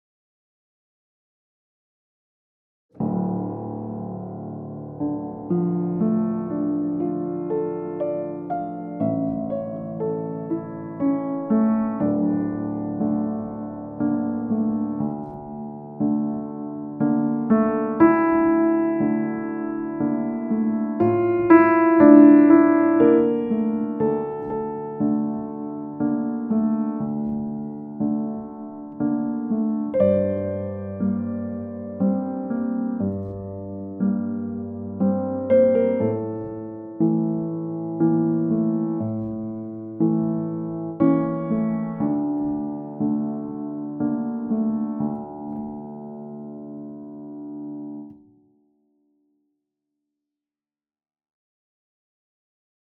· composición · audiovisual · clásico ·